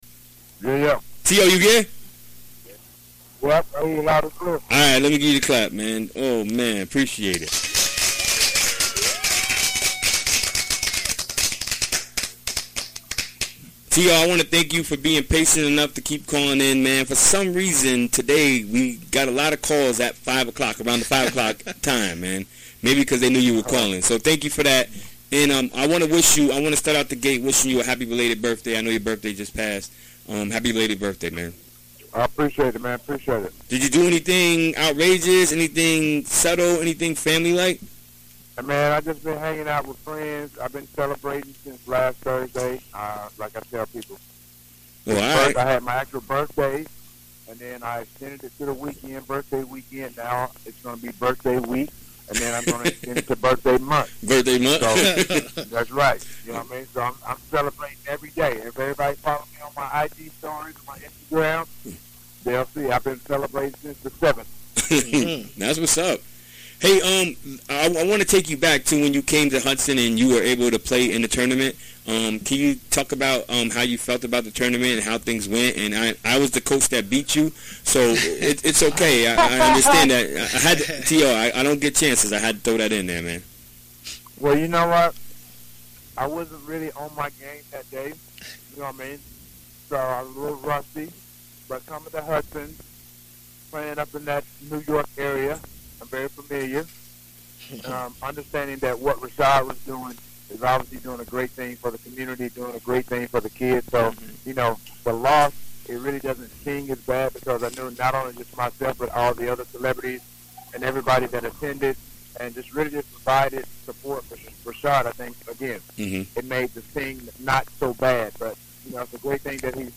NFL star Terrell Owens calls in to give an update on his latest activities. (Audio)
Recorded during the WGXC Afternoon Show Wednesday, December 13, 2017.